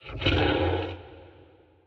Minecraft Version Minecraft Version 1.21.5 Latest Release | Latest Snapshot 1.21.5 / assets / minecraft / sounds / mob / horse / skeleton / water / idle4.ogg Compare With Compare With Latest Release | Latest Snapshot